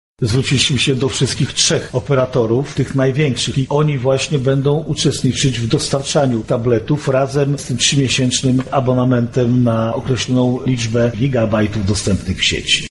Przejrzeliśmy swoje wydatki i jesteśmy w stanie kupić około 2200 tabletów – mówi Jarosław Stawiarski, Marszałek Województwa Lubelskiego